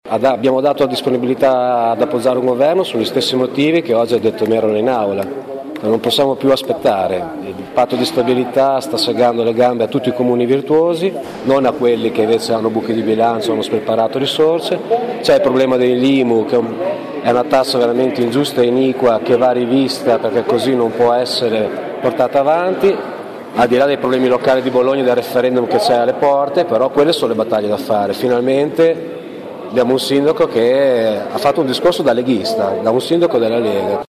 Dopo aver stretto la mano a Merola, Bernardini è uscito dall’aula e ha rivolto ai giornalisti le sue parole di apprezzamento.